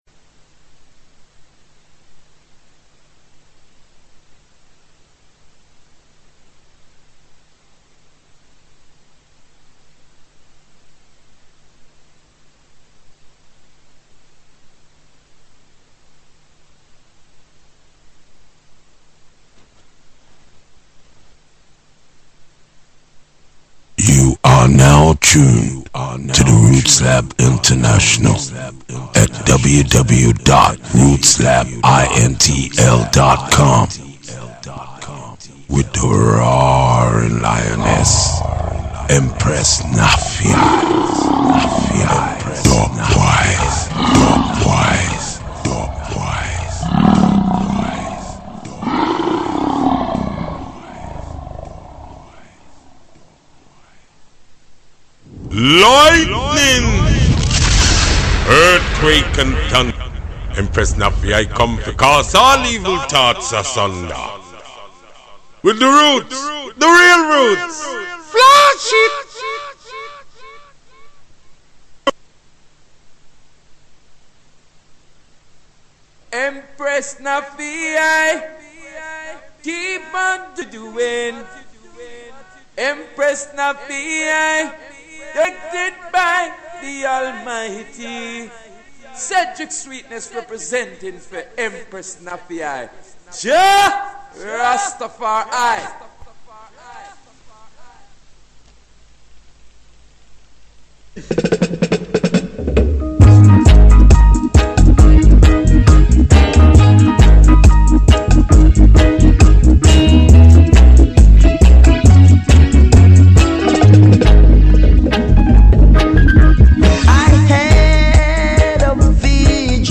LIVE INTERVIEW WITH THE ARTIST / PRODUCER / MUSICIAN / THE MOST WANTED PRE-AP BUILDER MAN WORLD WIDE...